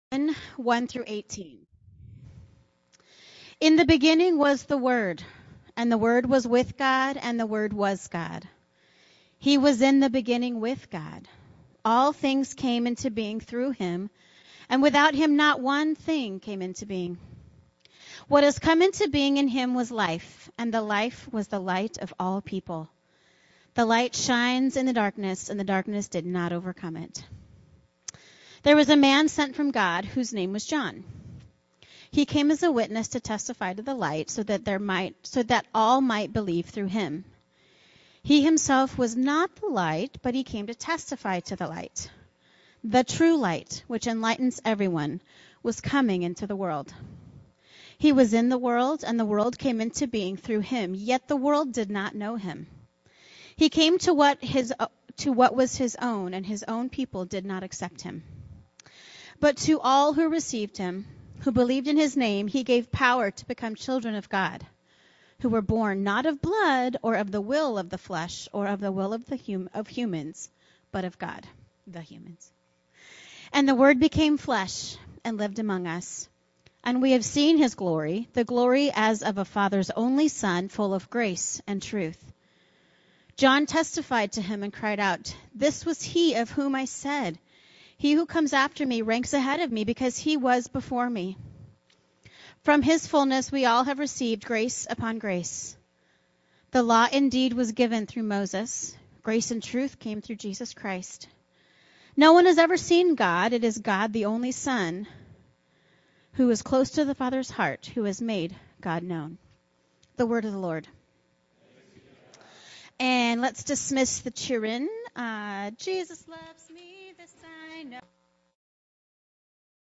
The Denominalization of Faith – East County Church of Christ